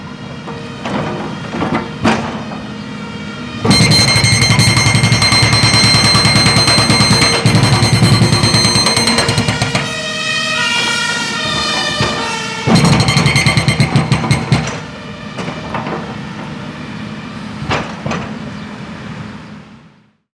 AtmoBerlin.wav